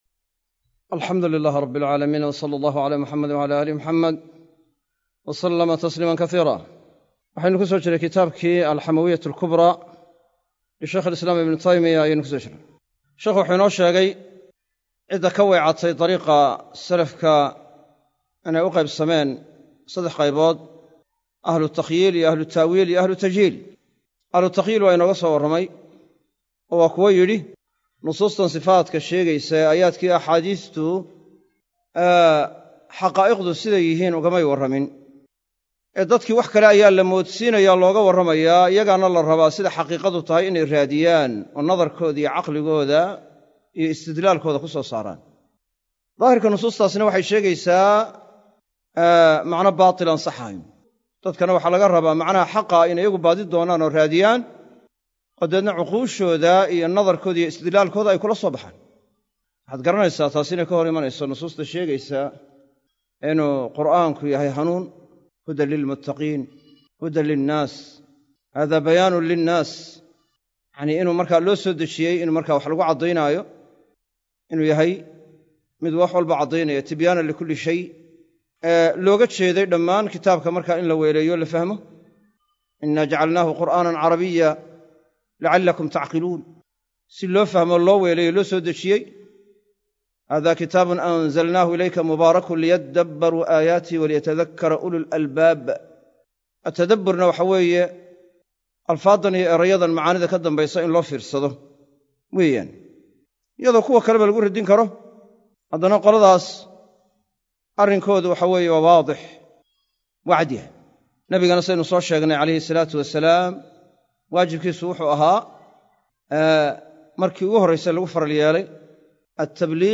Sharaxa Kitaabka Al-Fatwa Al-Xamawiyyah Al-Kubraa - Darsiga 13aad - Manhaj Online |